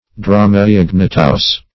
Search Result for " dromaeognathous" : The Collaborative International Dictionary of English v.0.48: Dromaeognathous \Dro`m[ae]*og"na*thous\, a. [NL. dromaius emu + Gr. gna`qos jaw.]
dromaeognathous.mp3